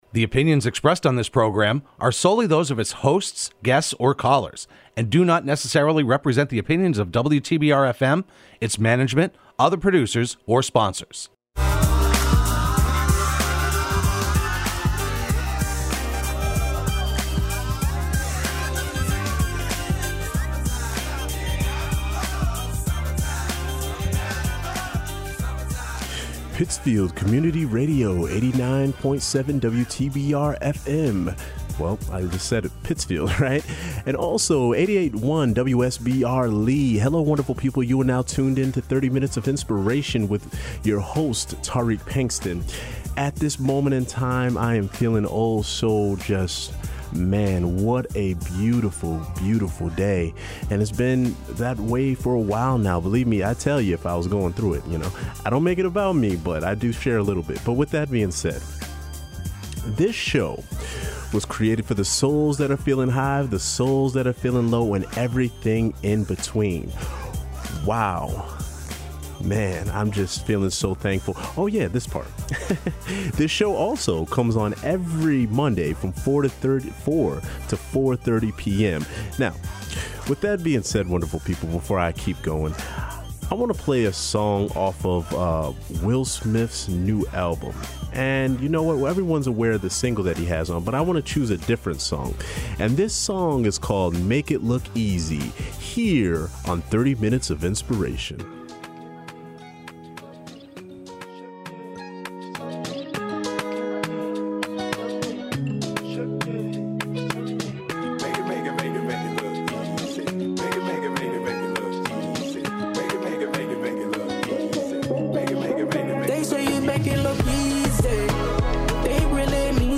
broadcast live every Monday afternoon at 4pm on WTBR